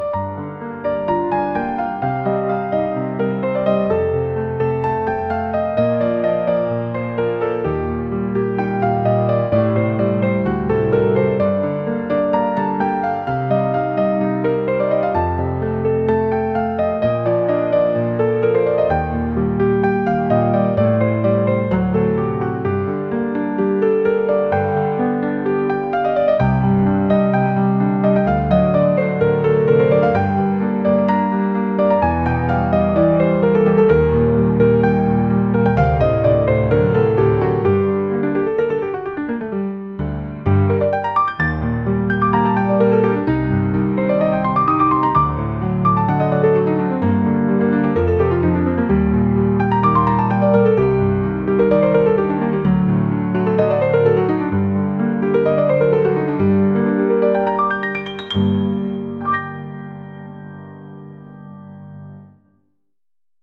決意を胸にして一歩踏み出すようなピアノの曲です。